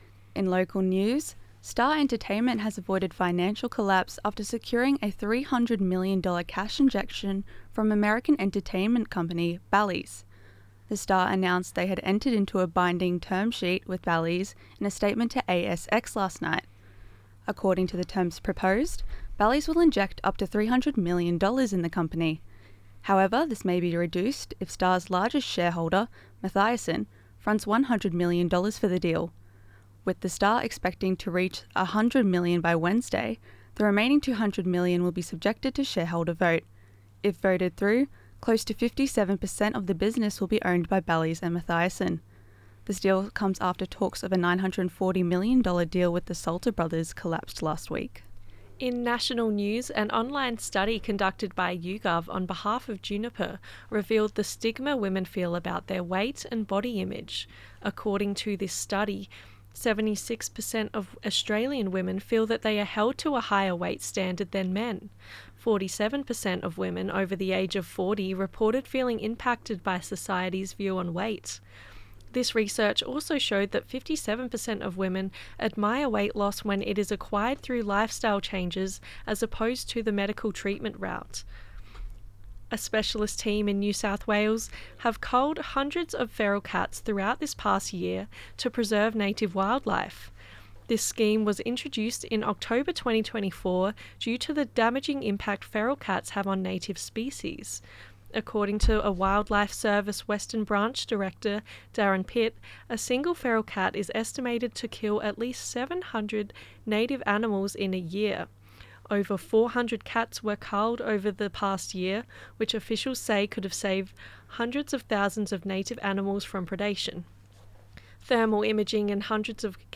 Zedlines Bulletin 9AM ZEDLINES 4.8.25.mp3